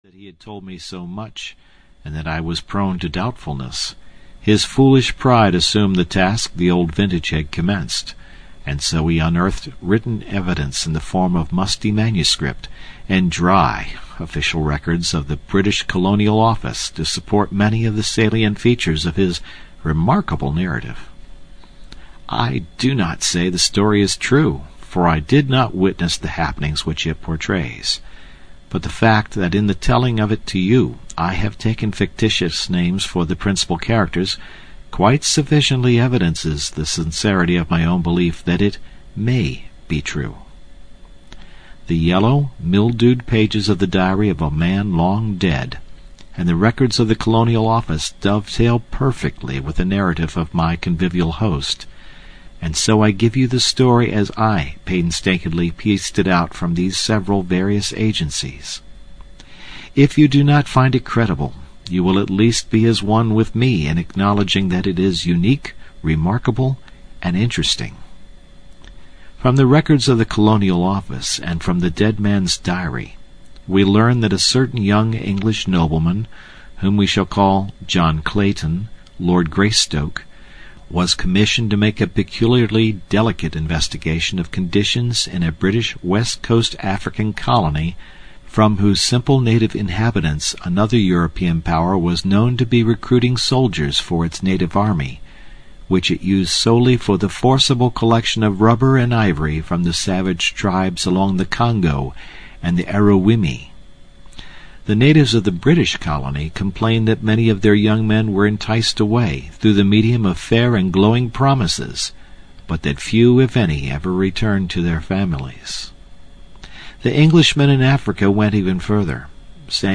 Tarzan of the Apes (EN) audiokniha
Ukázka z knihy